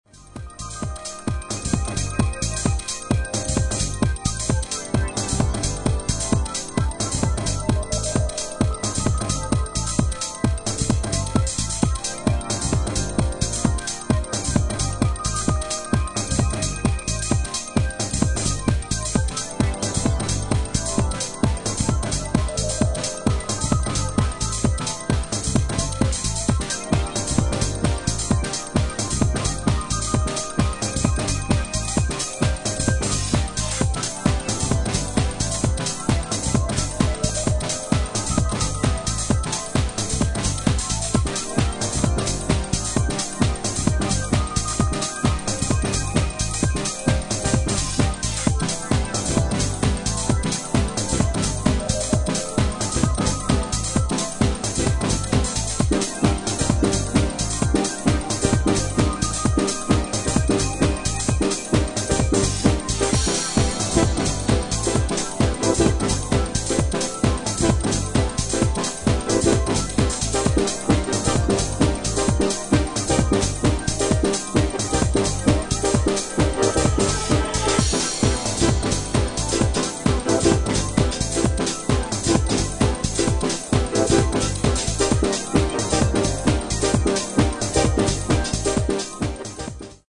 疾走感のあるビートを補うかのように、デトロイトテクノ・インフルエンスなエモーショナルなシンセサウンドを全面に展開し